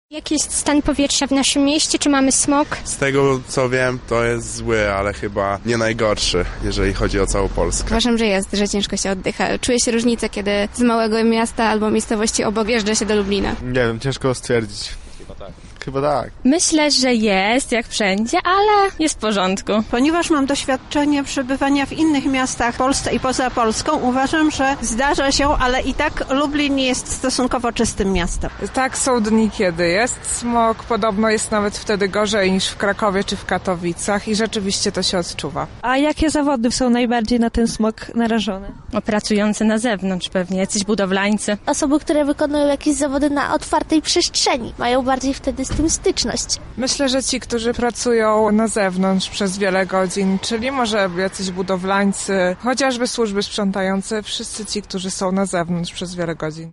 Zapytaliśmy mieszkańców Lublina czy odczuwają smog w mieście i kto może być nim najbardziej zagrożony
sonda smog